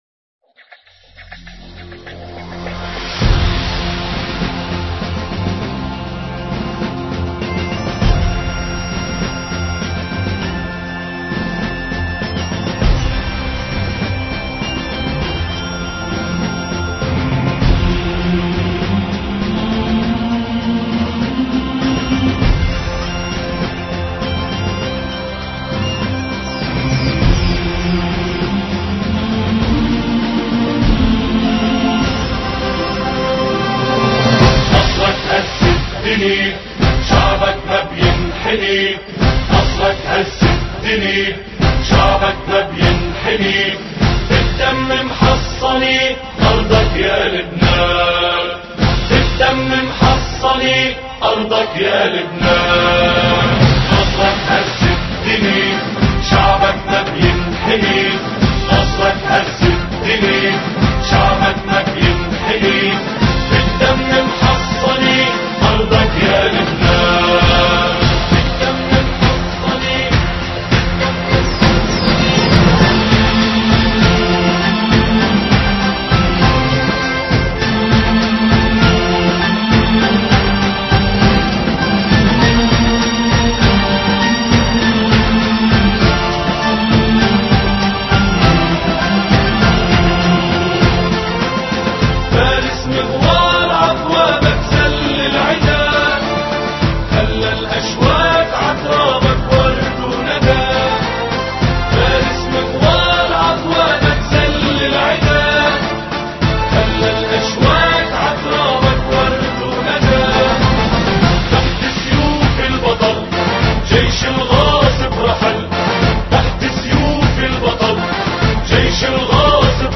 أناشيد لبنانية